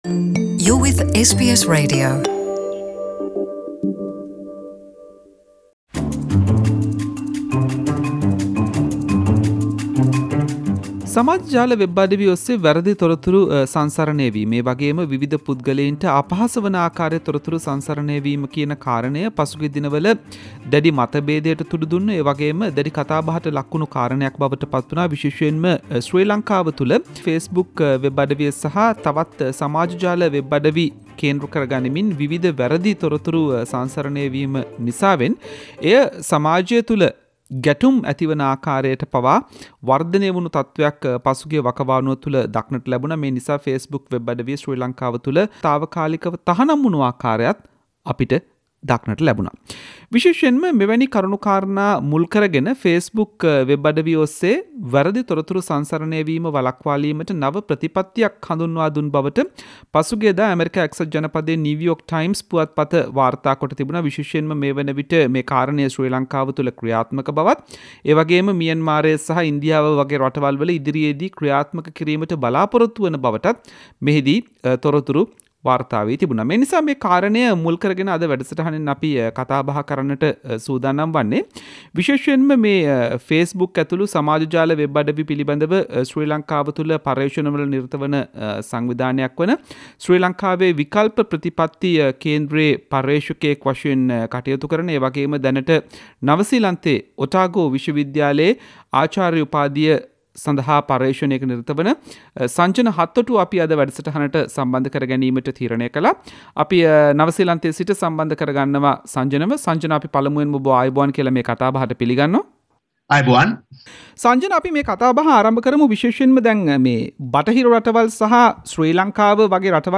SBS සිංහල වැඩසටහන කල සාකච්චාවක්.